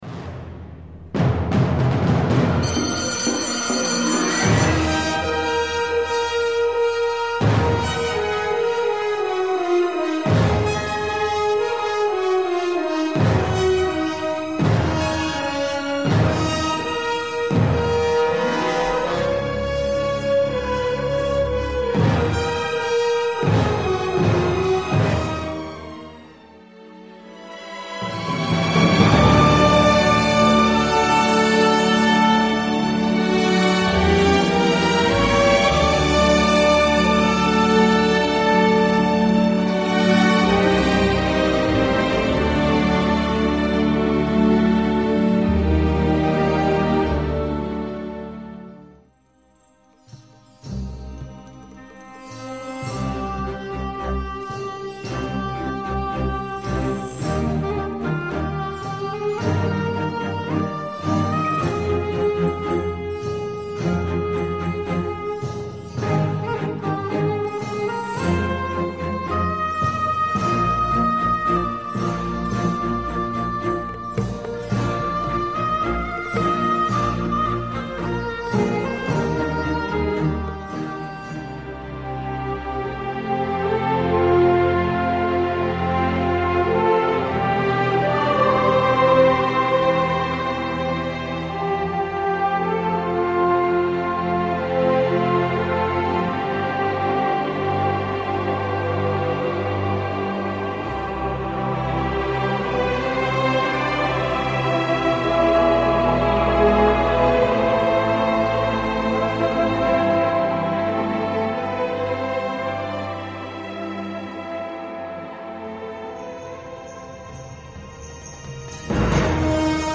Etwas getragen